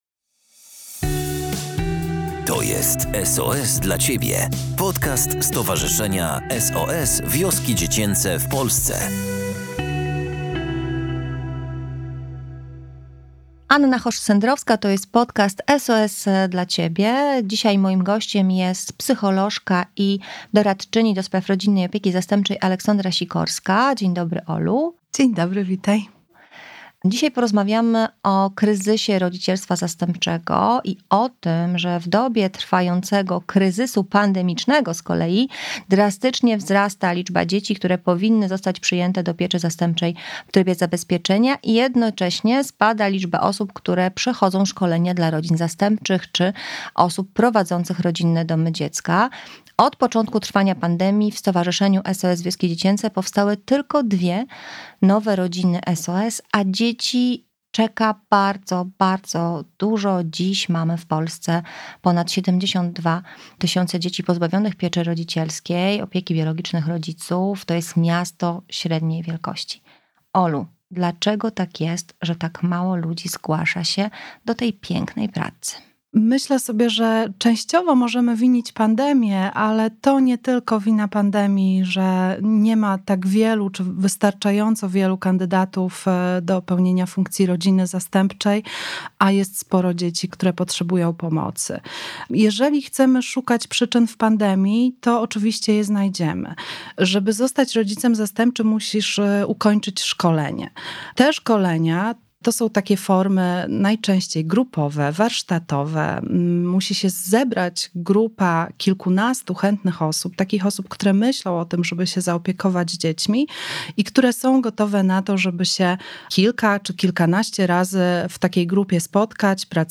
Kryzys pieczy zastępczej w Polsce – rozmowa